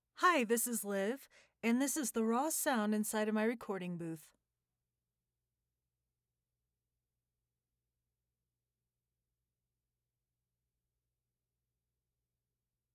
StudioSound2025.mp3